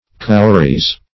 Cowries - definition of Cowries - synonyms, pronunciation, spelling from Free Dictionary
Cowrie \Cow"rie\ Cowry \Cow"ry\(kou"r[y^]), n.; pl. Cowries
(-r[i^]z).